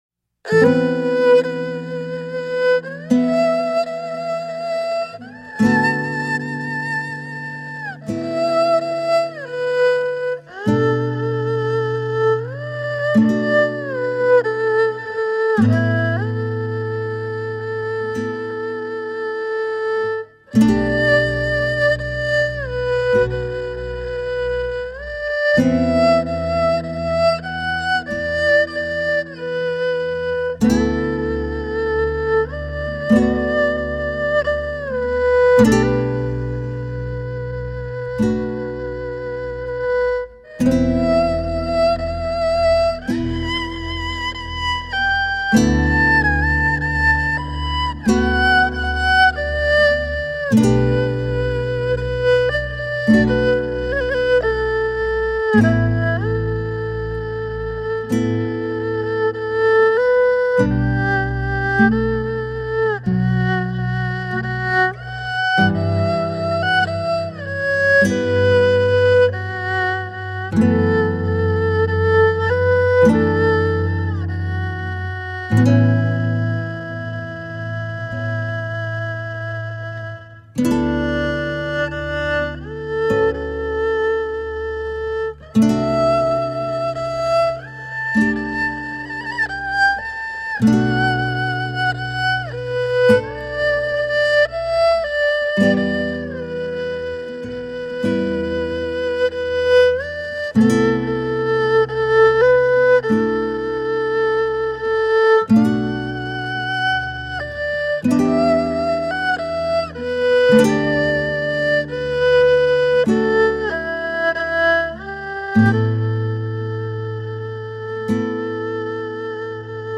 The album was recorded in Shanghai.
Tagged as: World, Instrumental World, Folk